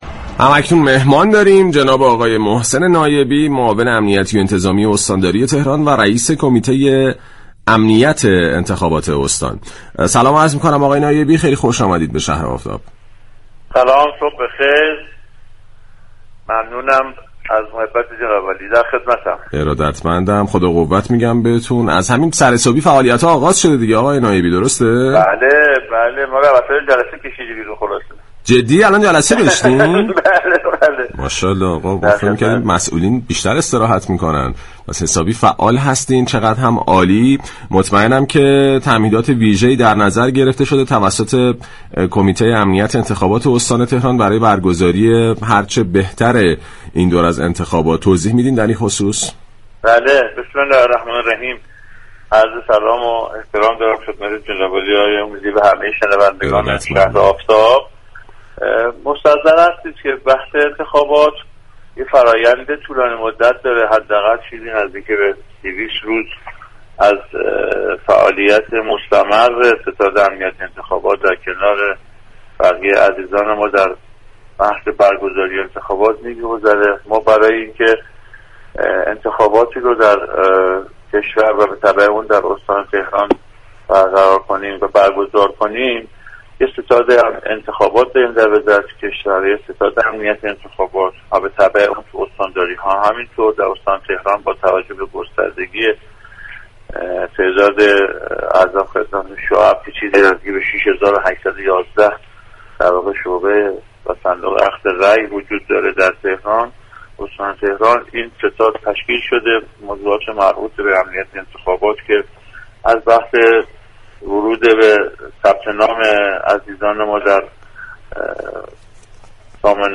گفت و گو